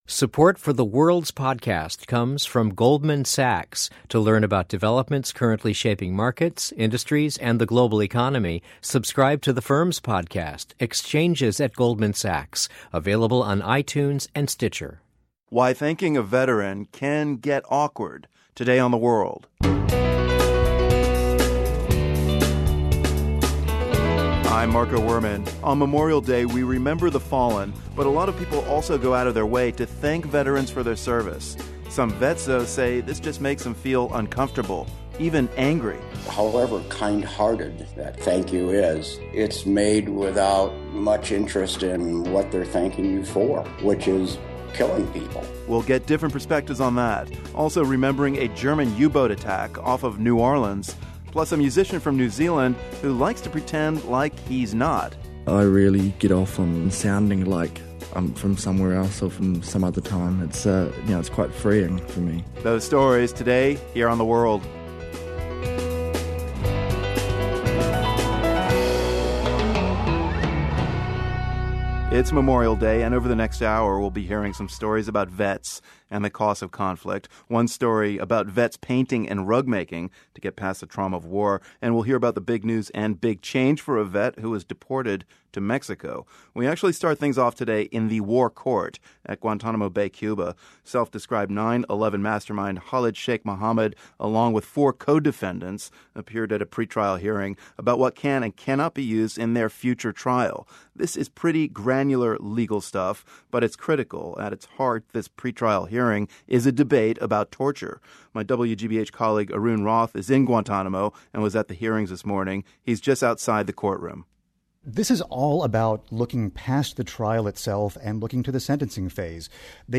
Today, we ask why Memorial Day can sometimes feel awkward for veterans. We also get an update from Guantanamo where pre-trial proceedings begin for the self-described 9/11 mastermind and his alleged co-conspirators. Plus, we hear music from a New Zealander whose work could be described as "Americana," but he says this kind of music doesn't belong just to Americans.